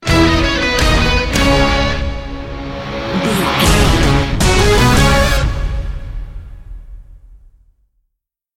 Ionian/Major
C#
horns
electric guitar
synthesiser
strings